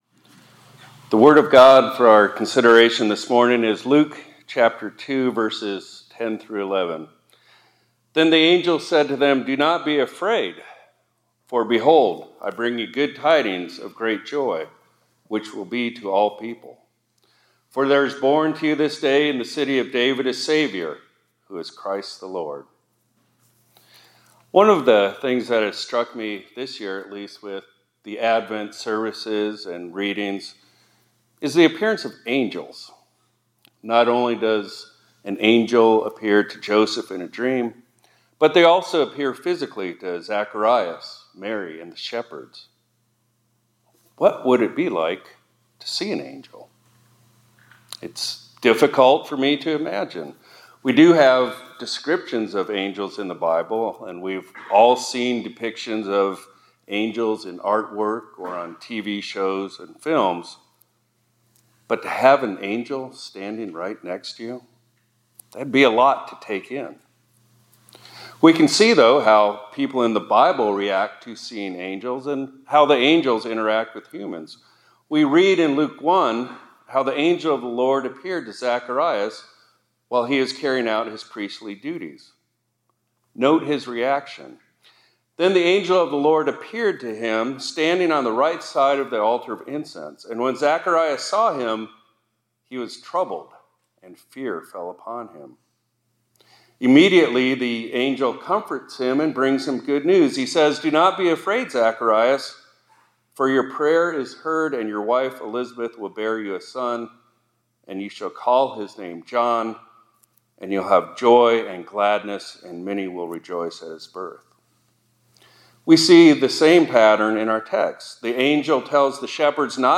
2024-12-16 ILC Chapel — Don’t Be Afraid — You Are Saved – Immanuel Lutheran High School, College, and Seminary